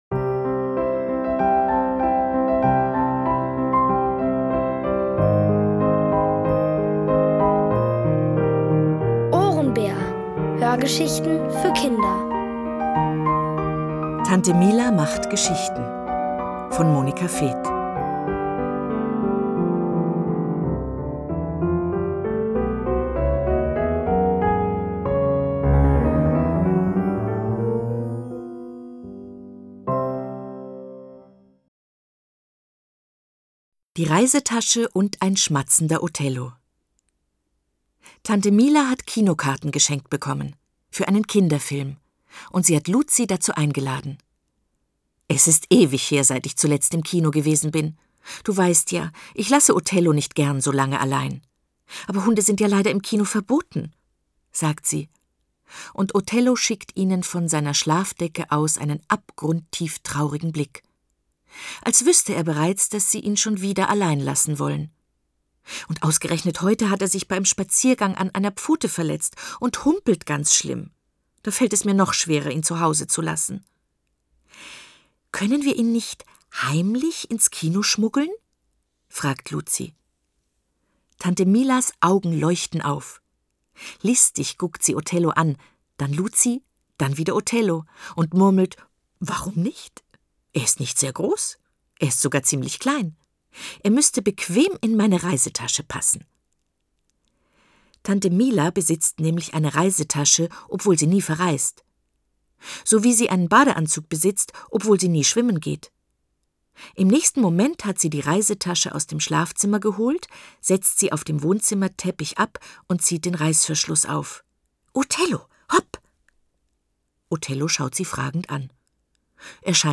Von Autoren extra für die Reihe geschrieben und von bekannten Schauspielern gelesen.
Es liest: Eva Mattes.